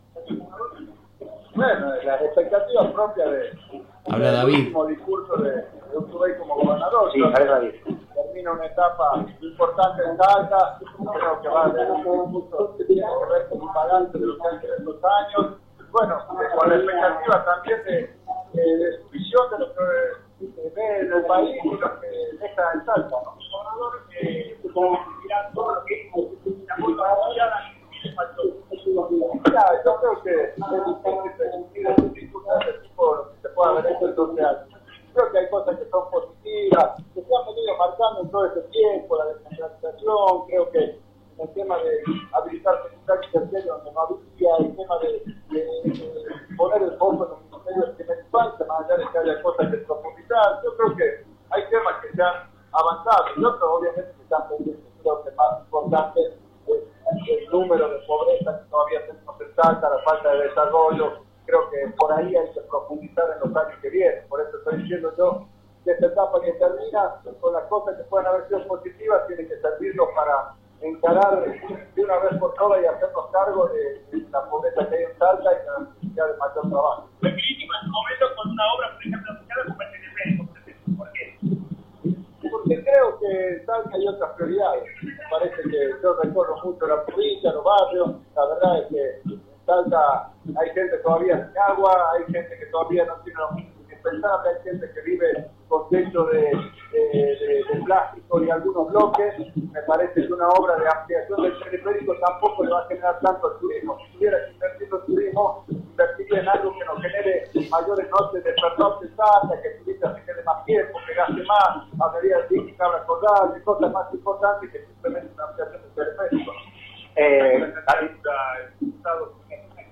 El diputado nacional y precandidato a gobernador Javier David se refirió al gobierno nacional y a su campaña electoral.